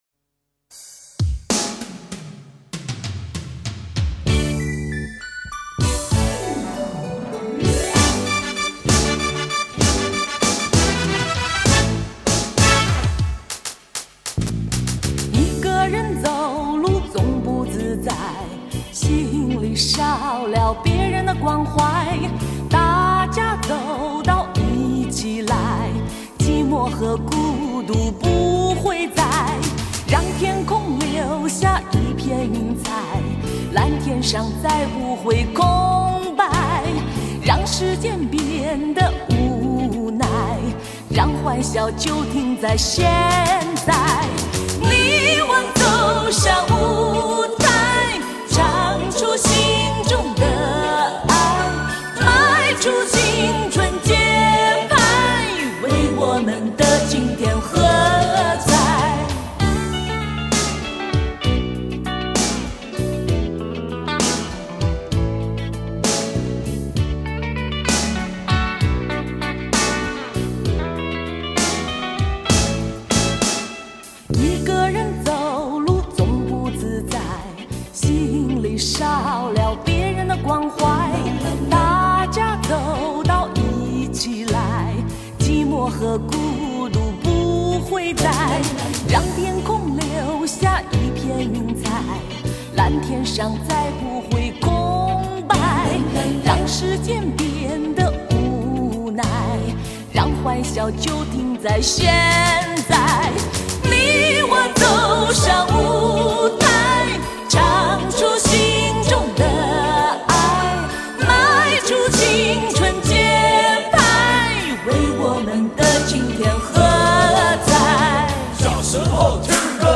她保持着音质中那份极具风格的碰性与特有的穿透力